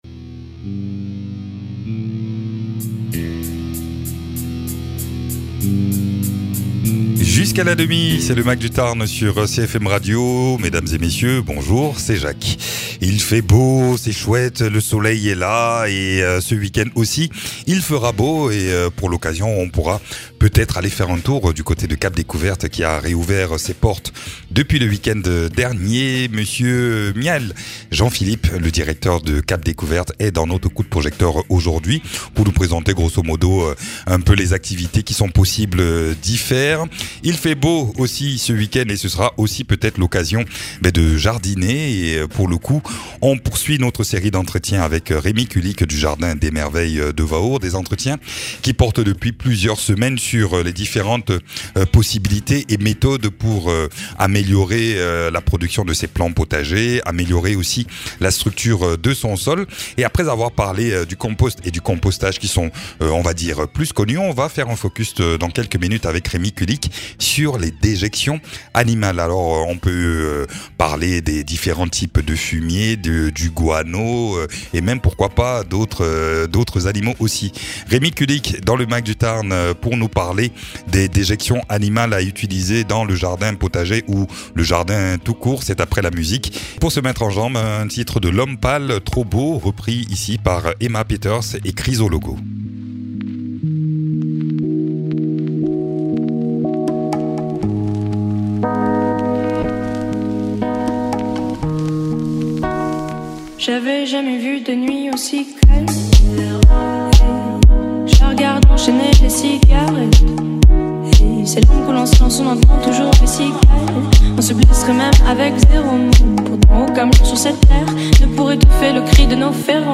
jardinier permaculteur